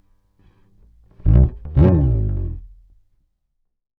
PHPH_bass_slide_03_120.wav